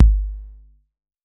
Modular Kick 05.wav